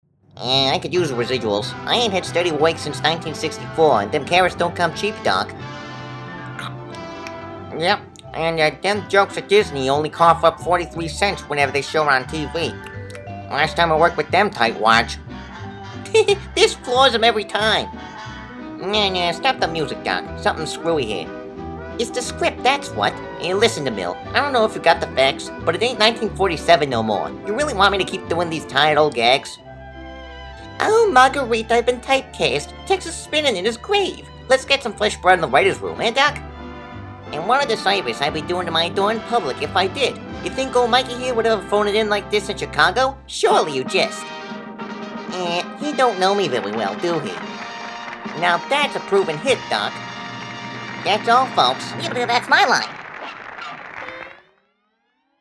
I didn't recite lines from other characters who appear in the strip- only the Looney Tunes characters. This is a voice I been doing forever but never actually got to record.